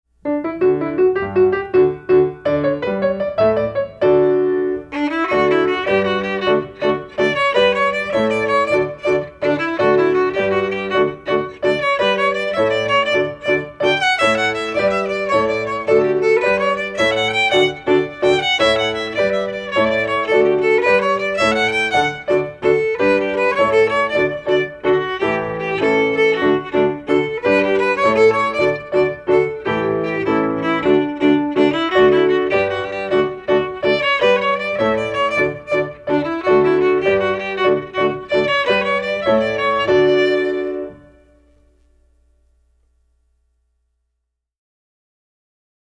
Voicing: Violin